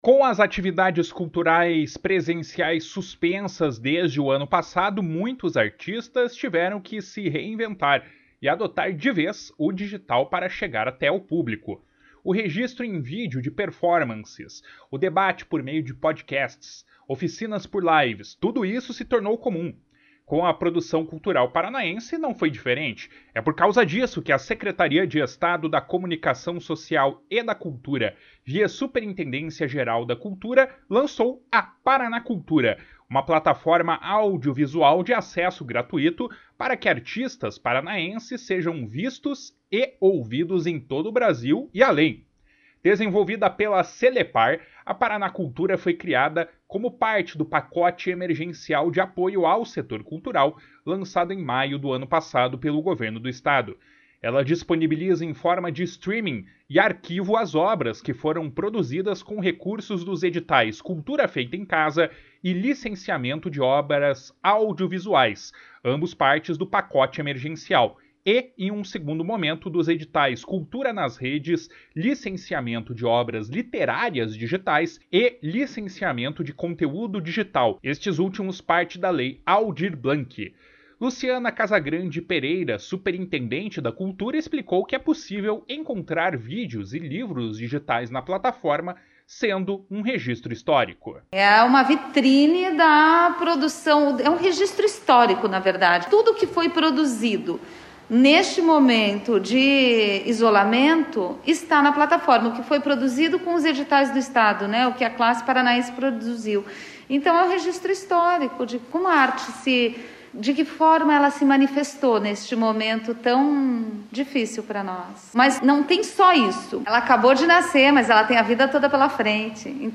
// SONORA LUCIANA CASAGRANDE PEREIRA // Além disso, são exibidos shows, lives, palestras, rodas de conversa e qualquer evento que possa ser captado por uma câmera e transmitido pela internet.